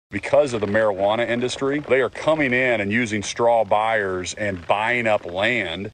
CLICK HERE to listen to commentary from Governor Stitt.